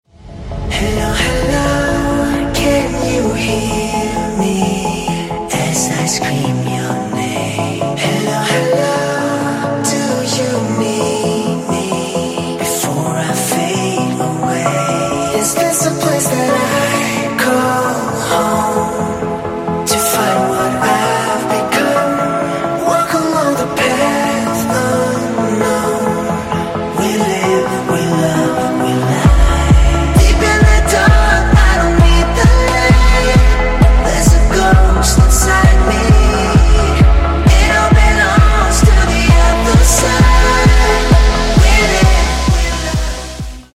• Качество: 192, Stereo
мужской вокал
dance
Electronic
EDM
спокойные
club
Melodic
romantic
vocal